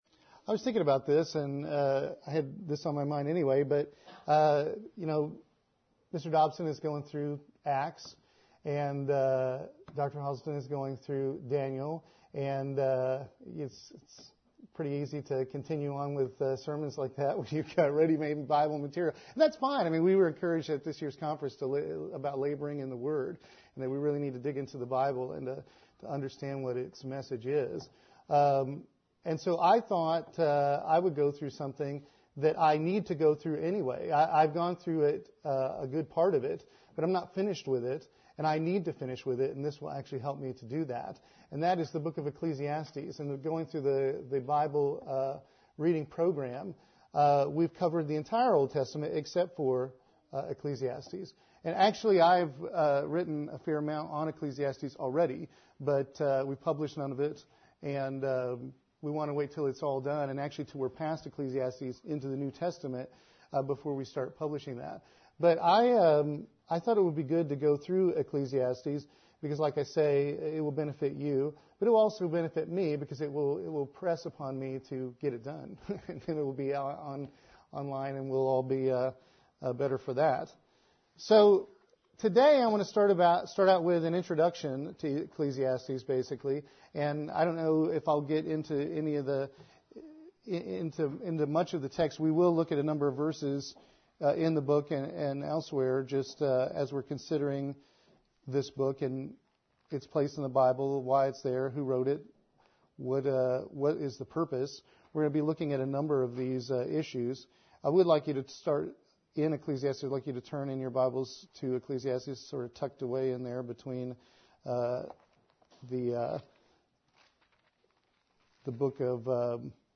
UCG Sermon The Book of Ecclesiastes Transcript This transcript was generated by AI and may contain errors.